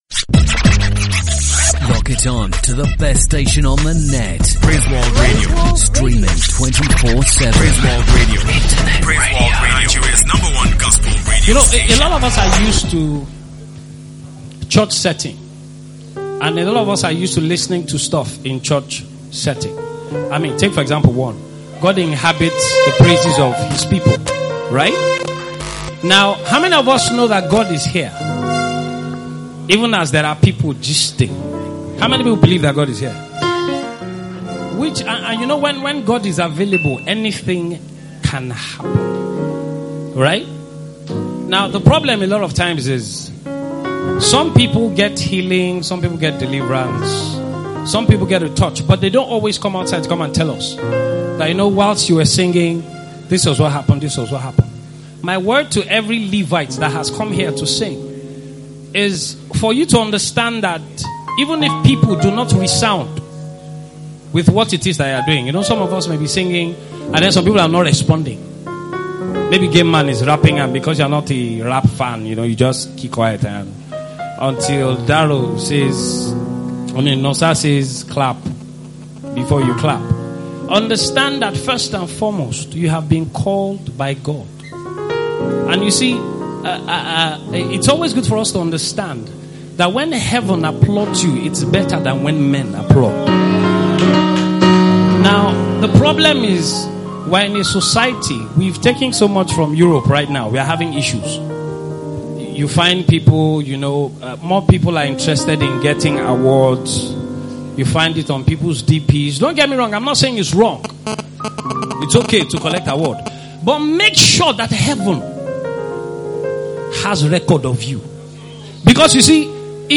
Show: LIVE broadcast of JAM Session (2nd Edition)